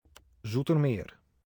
Pronunciation of Dutch city
Self made, in own studio, with own voice